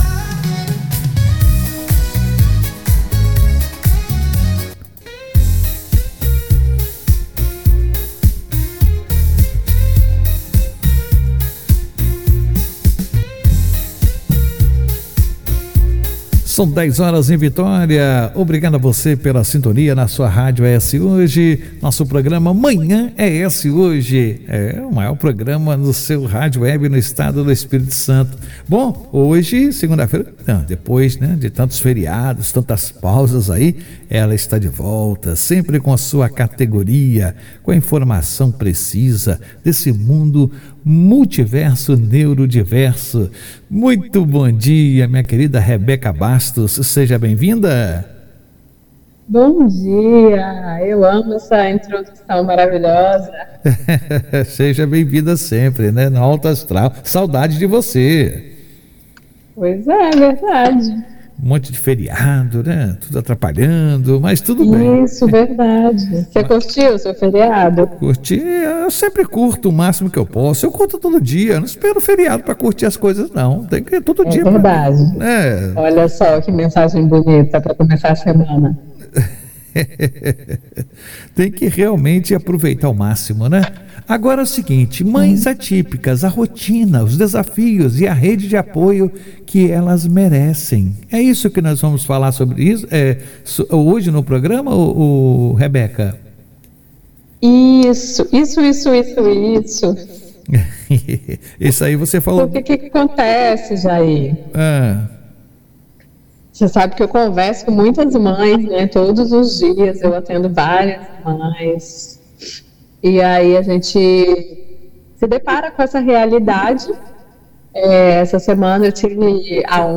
O quadro Multiverso Neurodiverso, da Rádio ES Hoje, está de volta trazendo à tona a realidade das mães atípicas.